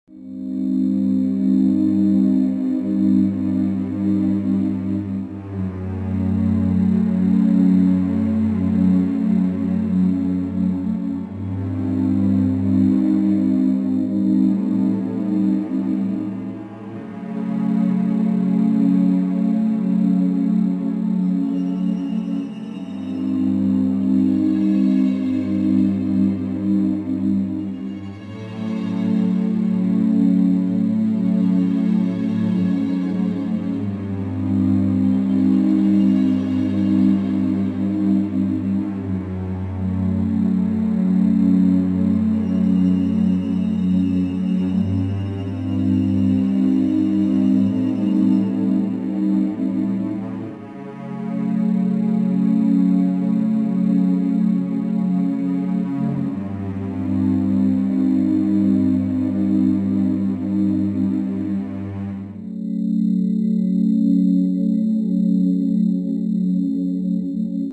Eerie-Thriller.mp3